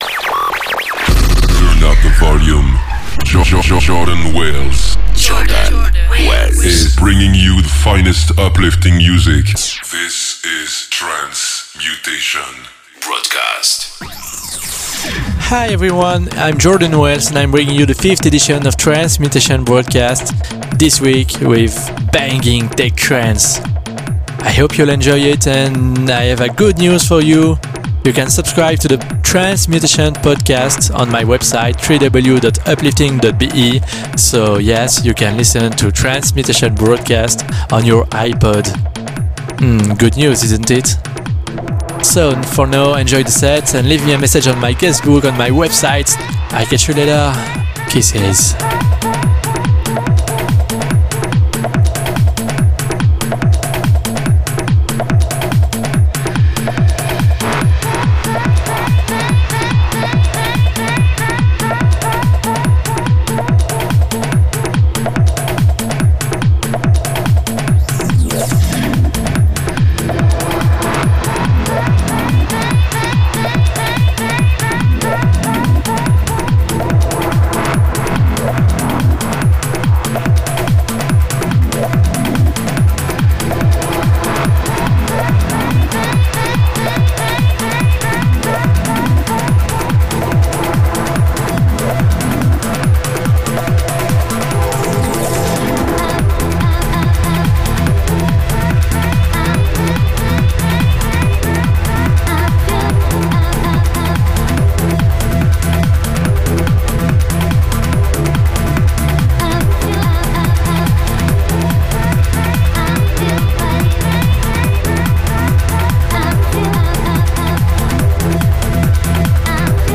60 minutes of the most banging big-room tech-trance
A set that will make you restless !